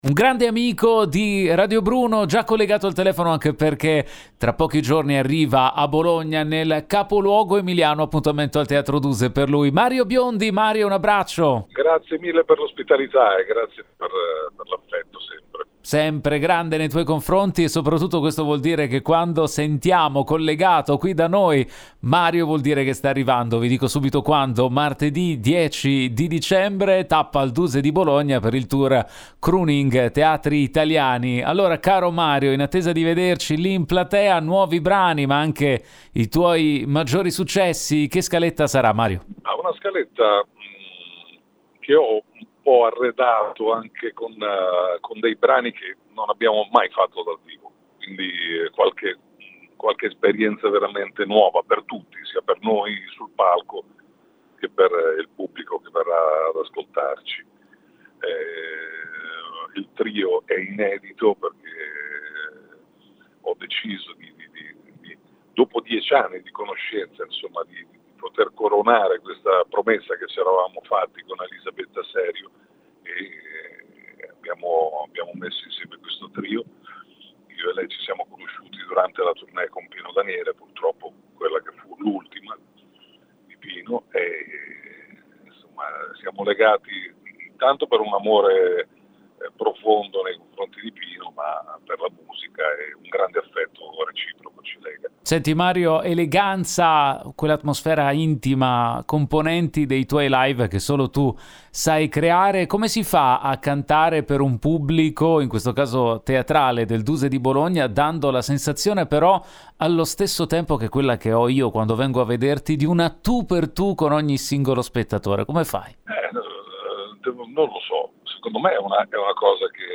Home Magazine Interviste Mario Biondi a Bologna con “Crooning – Teatri Italiani”